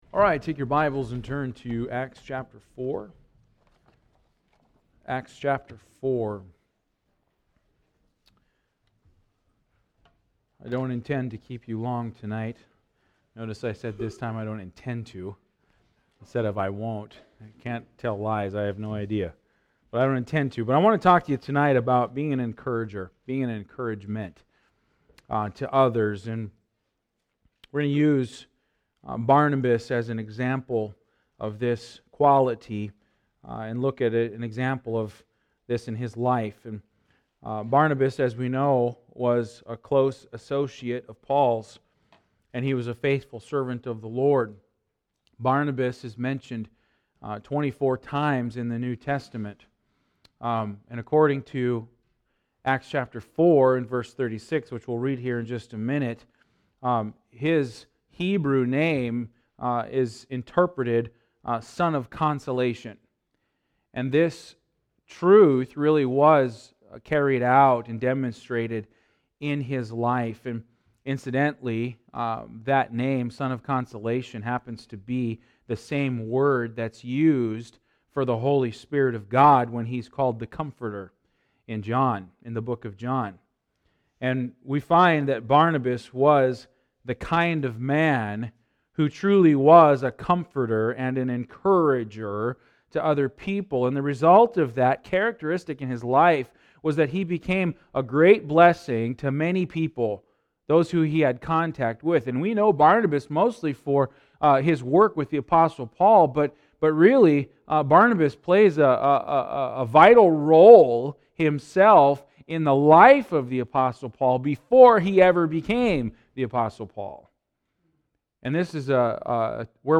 Passage: Philippians 2:1-5 Service Type: Wednesday Evening « 4-4-18 Nuggets